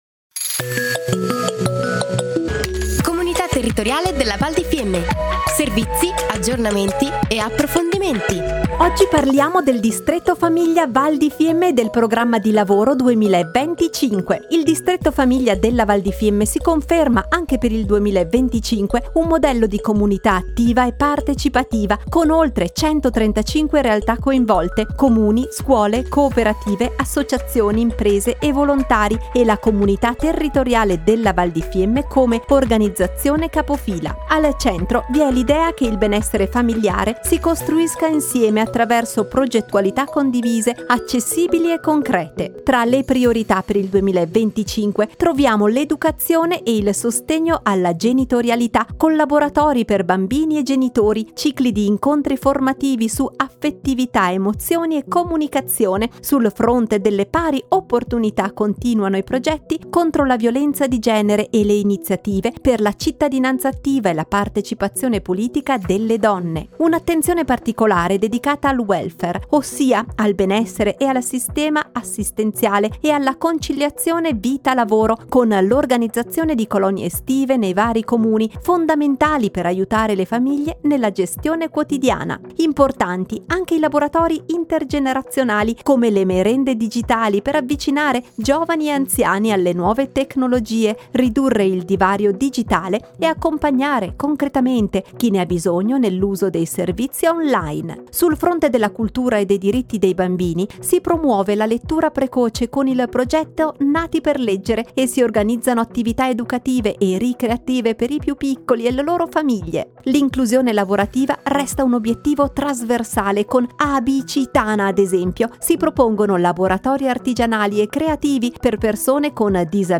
Puntata nr. 6 - Distretto Famiglia Val di Fiemme - Programma di Lavoro 2025 / Anno 2025 / Interviste / La Comunità si presenta tramite Radio Fiemme / Aree Tematiche / Comunità Territoriale della Val di Fiemme - Comunità Territoriale della Val di Fiemme